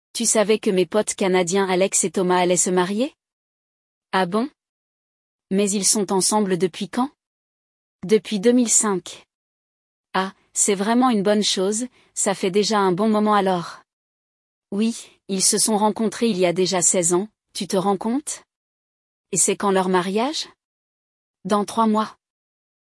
Descubra como falar sobre frequência ouvindo o diálogo entre nativos de hoje!
Le dialogue